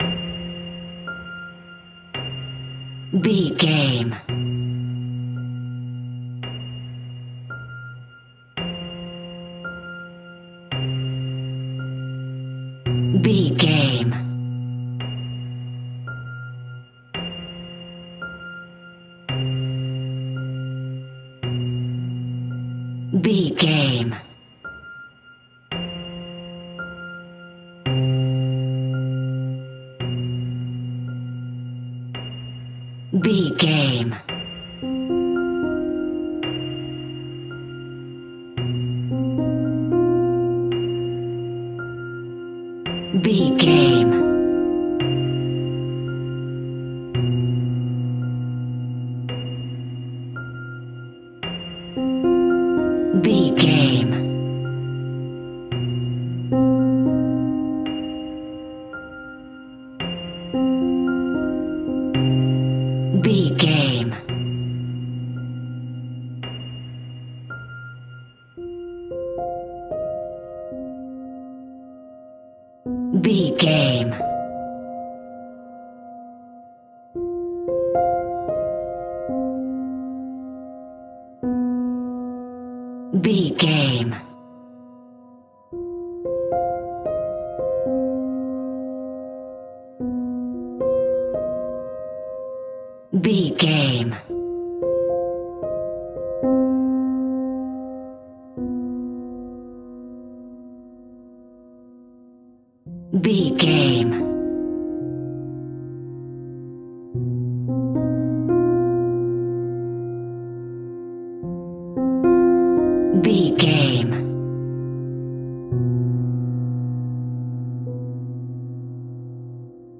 Aeolian/Minor
scary
tension
ominous
eerie
instrumentals
horror piano